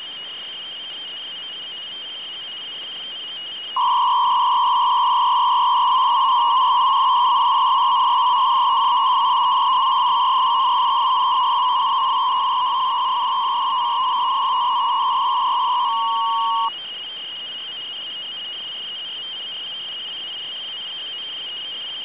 Начало » Записи » Радиоcигналы на опознание и анализ
Сигнал на анализ, QPSK 200 бод.